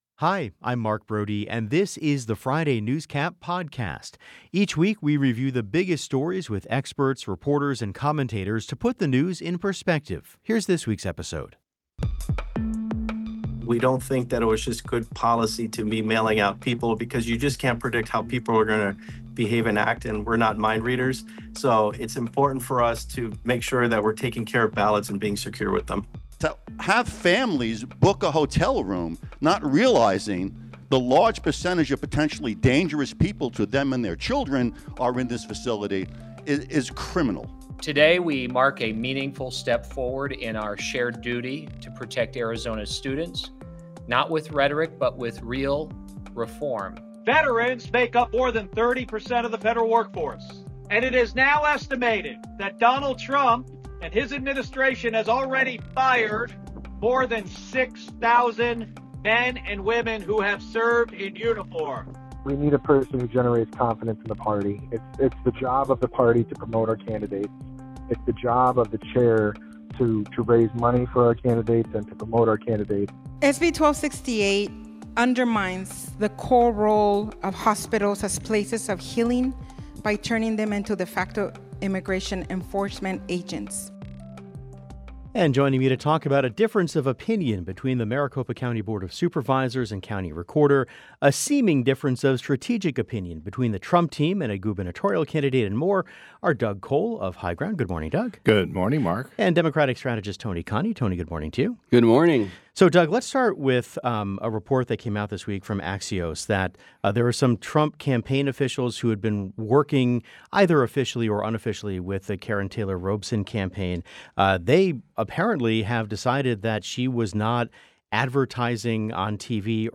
The Show's Friday Newscap is a weekly review of the biggest stories with experts, reporters and commentators to put the news in perspective.